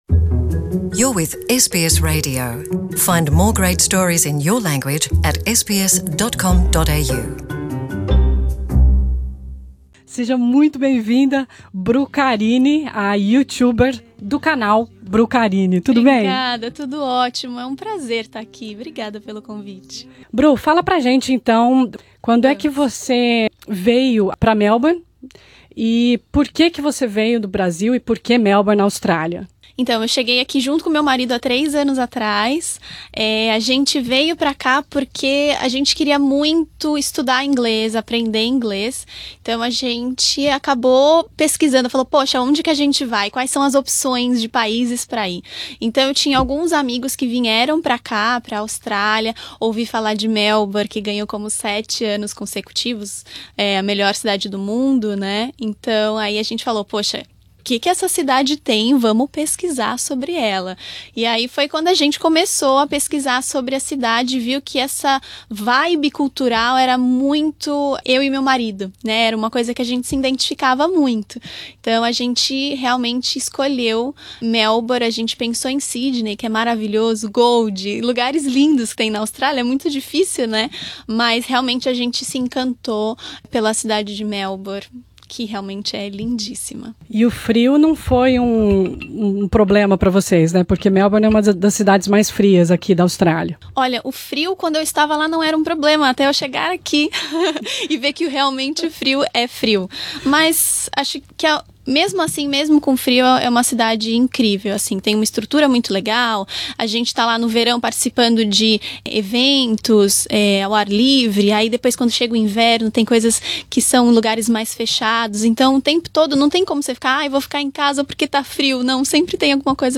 Nessa entrevista ela conta como foi se reinventar em solo australiano e dividir sua vida com milhões de seguidores.